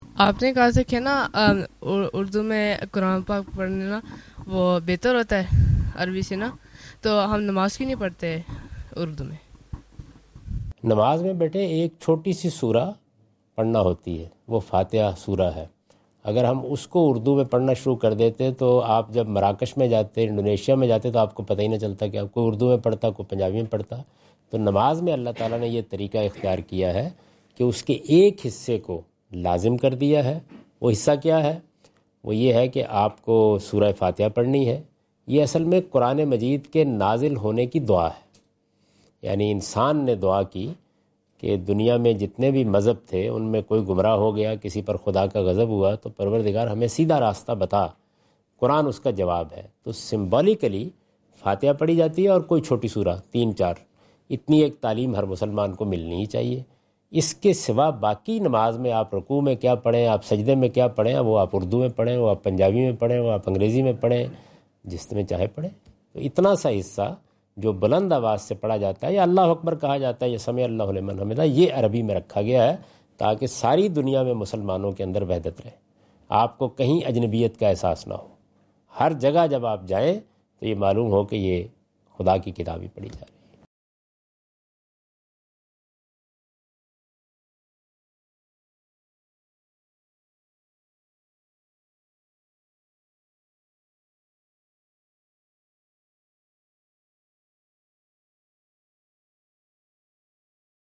Category: English Subtitled / Questions_Answers /
Javed Ahmad Ghamidi answer the question about "offering prayer in urdu language" during his Australia visit on 11th October 2015.
جاوید احمد غامدی اپنے دورہ آسٹریلیا کے دوران ایڈیلیڈ میں "اردو میں نماز پڑھنا" سے متعلق ایک سوال کا جواب دے رہے ہیں۔